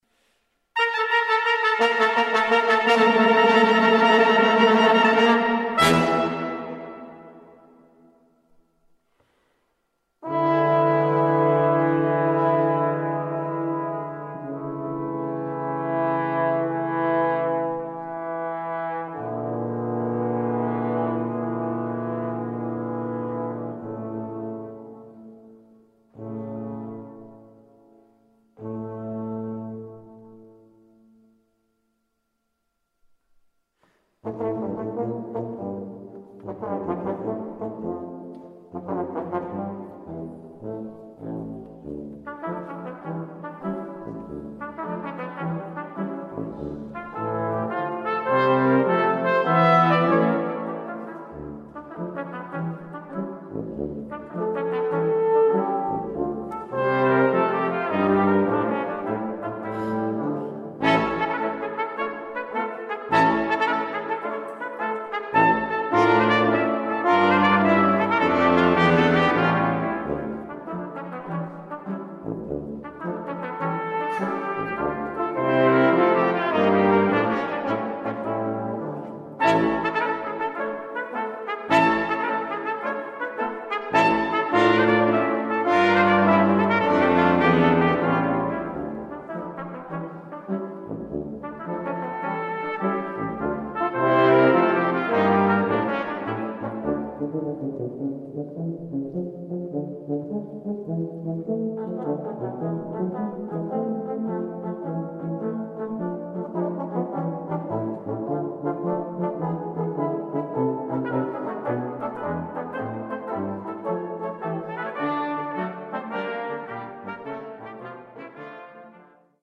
1st trumpet plays both Bb and piccolo trumpets.
(live performance)